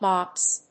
/mɑps(米国英語), mɑ:ps(英国英語)/